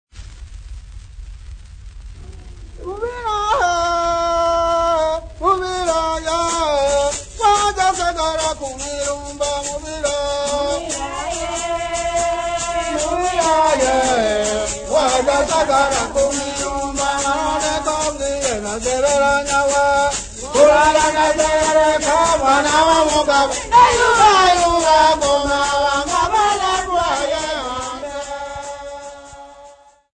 Nyamwezi women
Folk Music
Field recordings
Africa Tanzania city not specified f-tz
Indigenous music